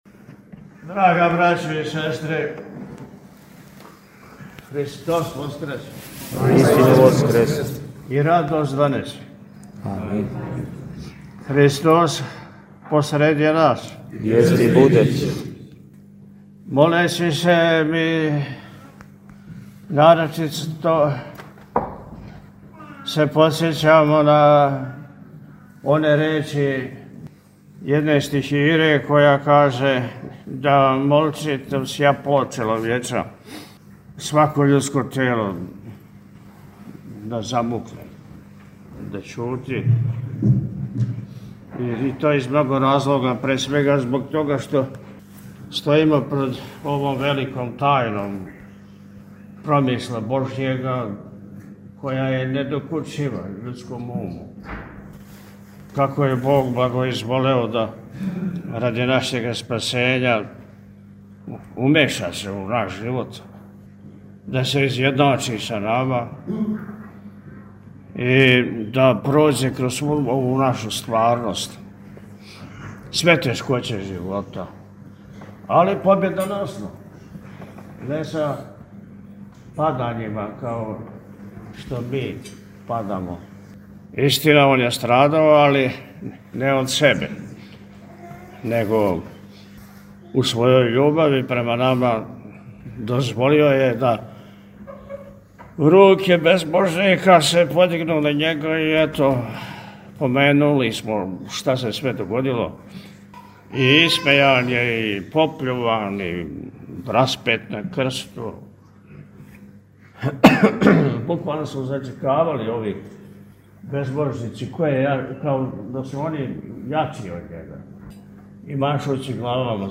Његово Високопреосвештенство Архиепископ и Митрополит милешевски г. Атанасије служио је на Велики Петак, 10. априла 2026. године, у Вазнесењском храму манастира Милешеве Вечерње са изношењем [...]
Беседу Митрополита Атанасија можете послушати овде: